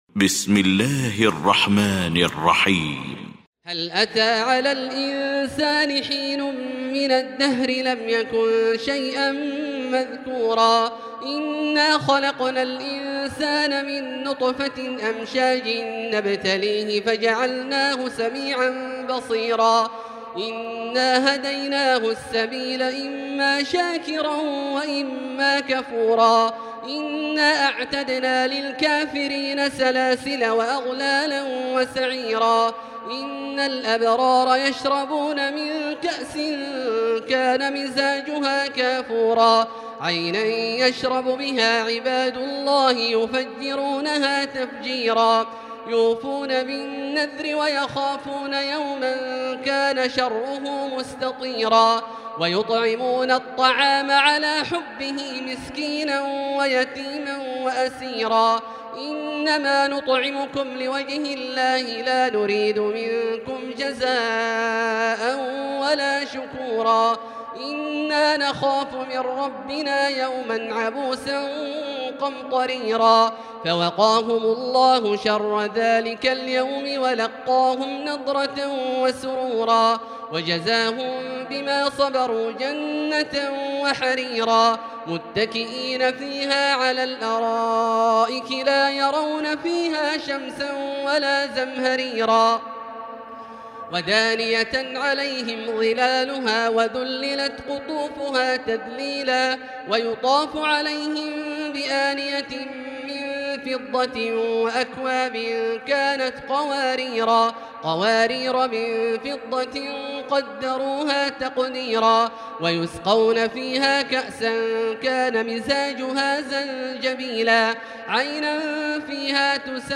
المكان: المسجد الحرام الشيخ: فضيلة الشيخ عبدالله الجهني فضيلة الشيخ عبدالله الجهني الإنسان